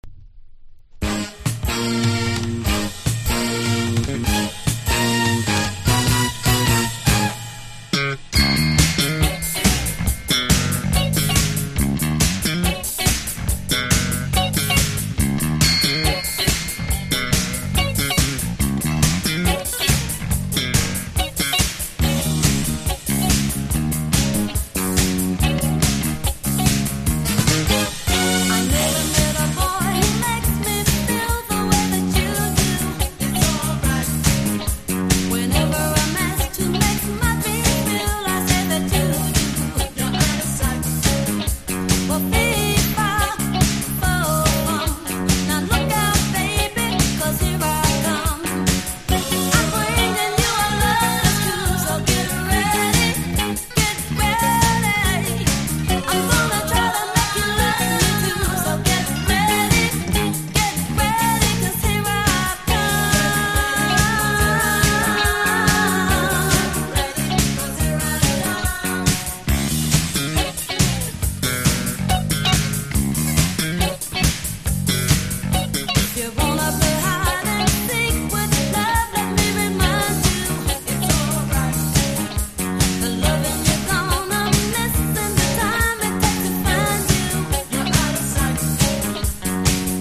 1. NEW WAVE >
ガラージクラシック含むNWジャズ～コールド・ファンクのスプリット盤。